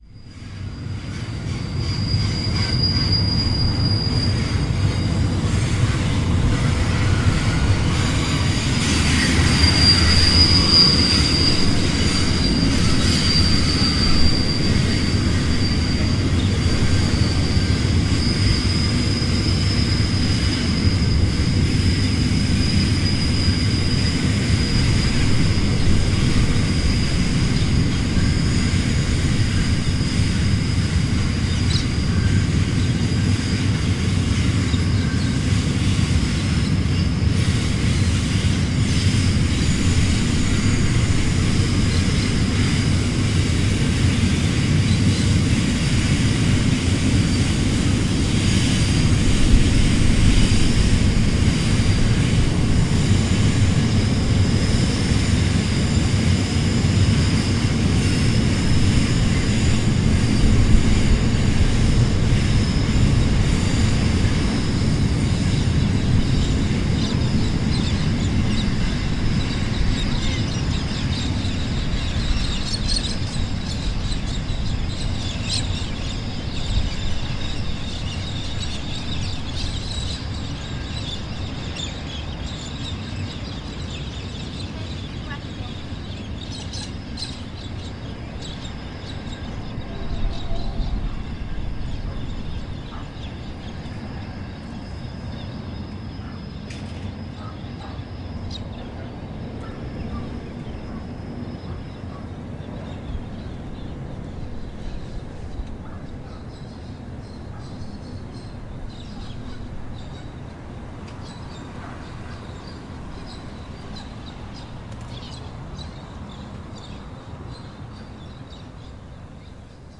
火车 " 钢上加钢火车经过
描述：我才抓住这个。穿过奥古斯塔港 南澳大利亚的货车的声音，。然后就消失了。 录制到Zoom f4上，在MS设置中安排了两个sennheiser麦克风。
Tag: 铁路 铁路 机车 货运列车 列车 铁路路 铁路 柴油 deisel引擎 铁路 公路 路口